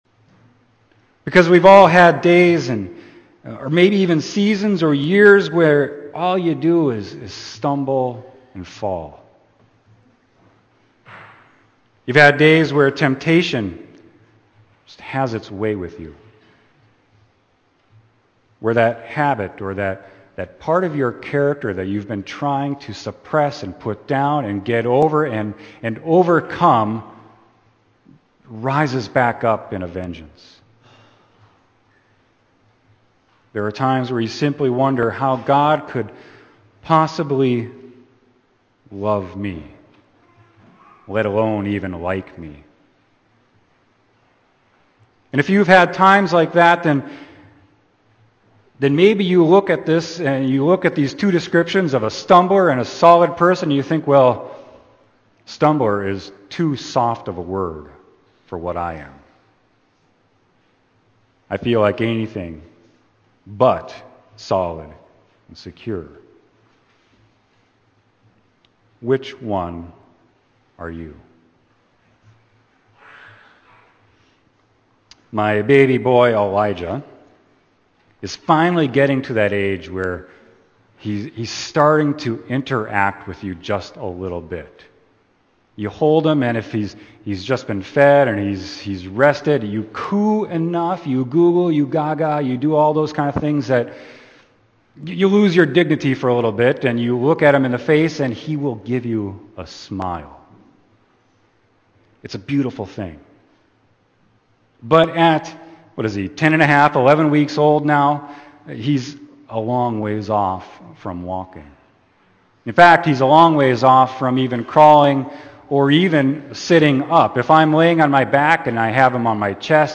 Sermon: 1 Peter 2.2-10